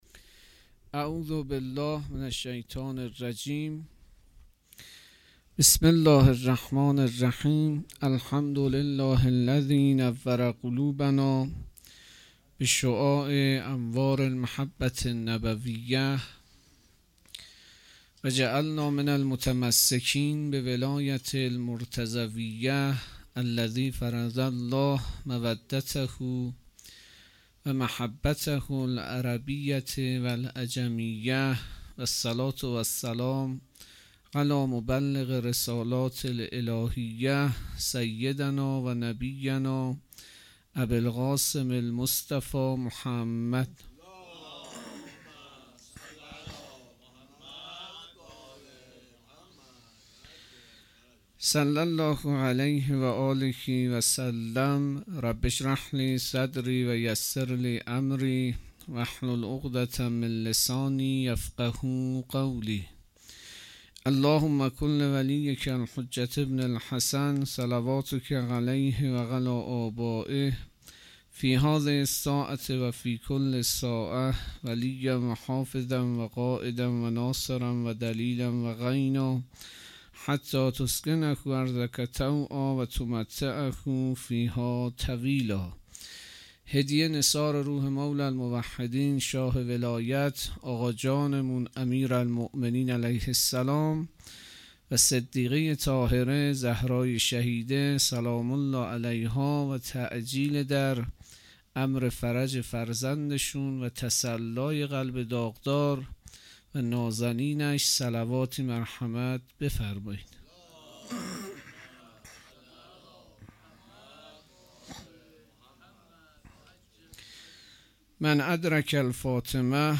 سخنرانی
اقامه عزای روضه حضرت صدیقه شهیده علیها السلام _ شب اول